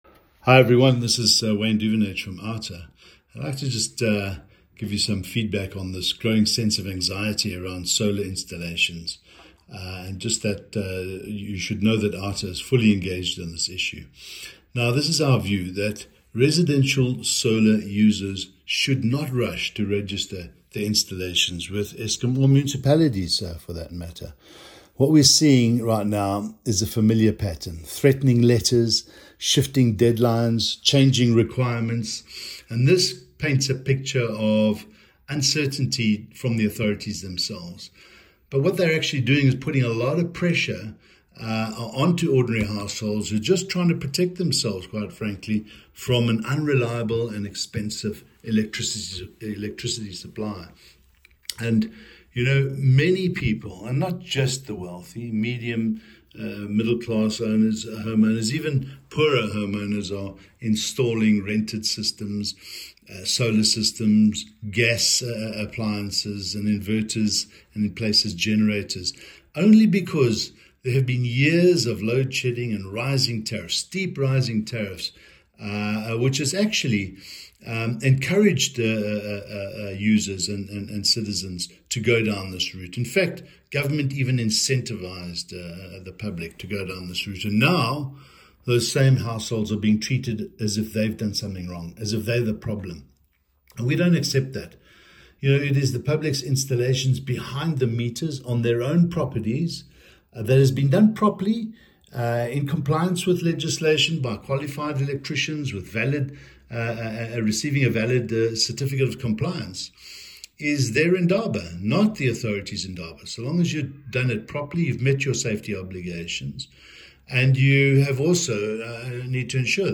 A soundclip with comment in English